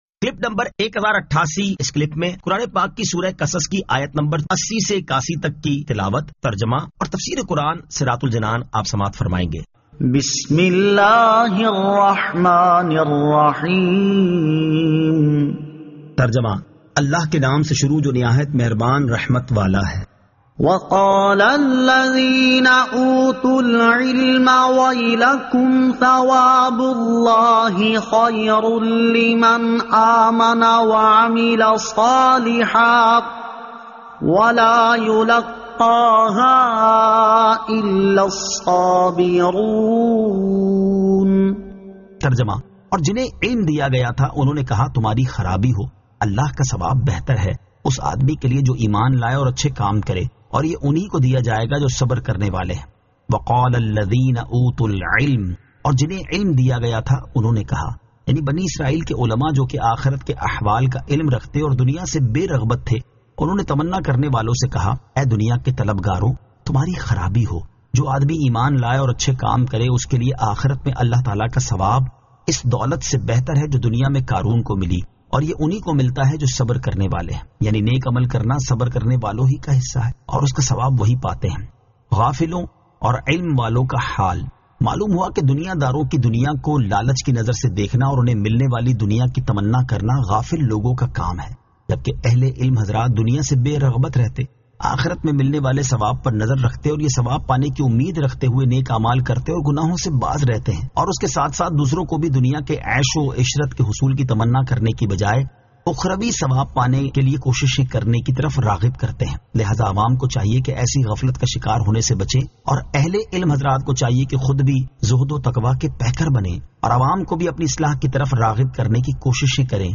Surah Al-Qasas 80 To 81 Tilawat , Tarjama , Tafseer